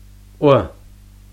ʔʷ audio speaker icon
ӏо Somewhat like Cockney network